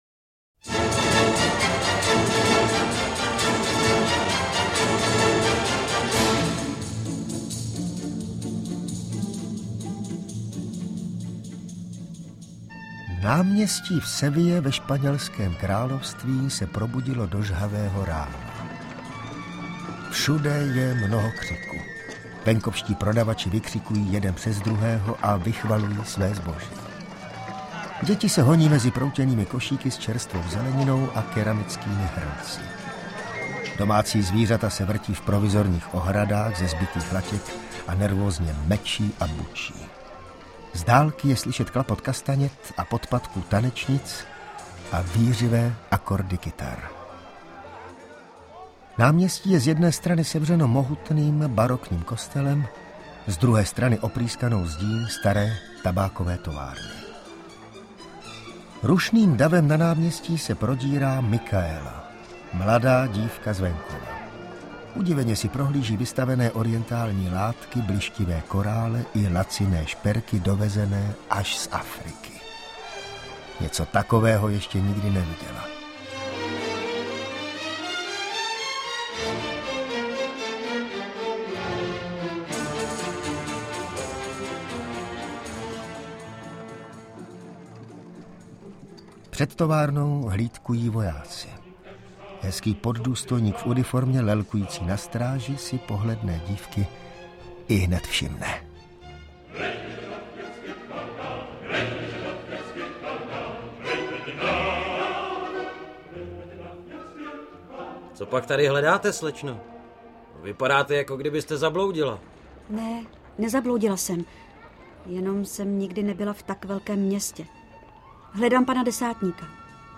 Slavný operní příběh v dramatizaci Jana Jiráně podle původního libreta s ukázkami nejslavnějších hudebních pasáží v podání největších českých operních pěvců!